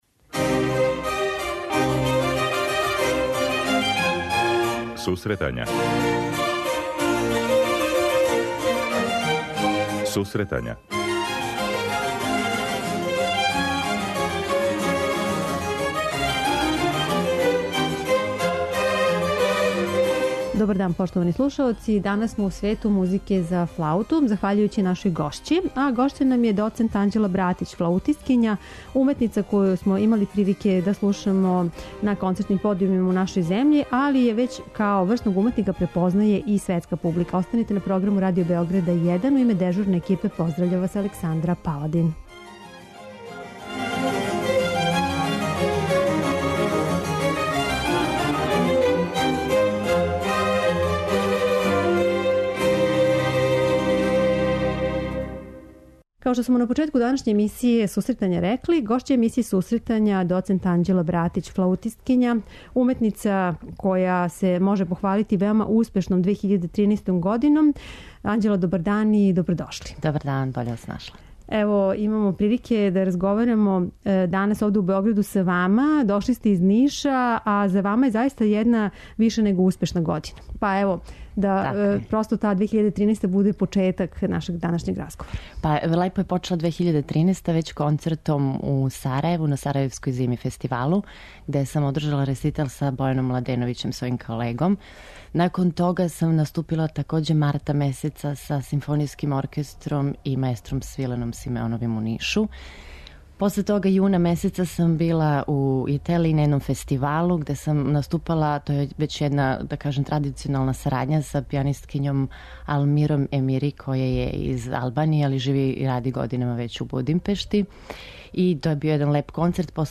преузми : 25.59 MB Сусретања Autor: Музичка редакција Емисија за оне који воле уметничку музику.